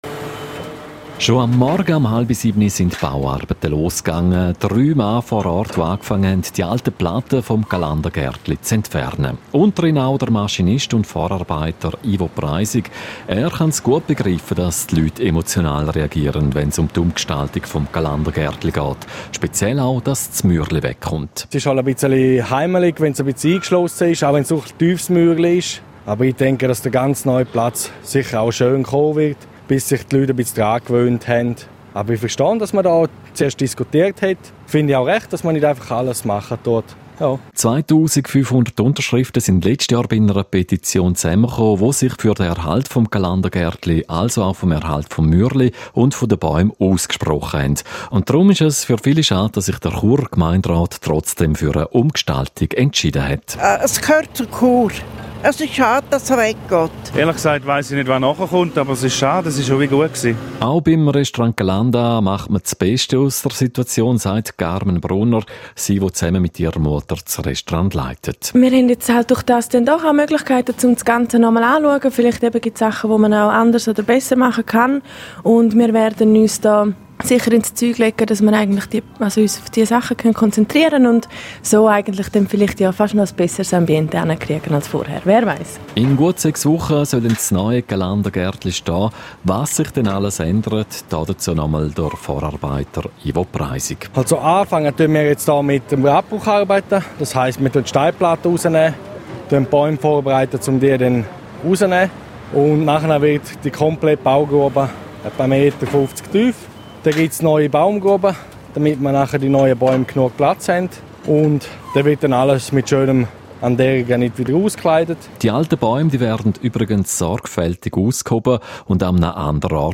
Auf Stimmenfang vor der Baustelle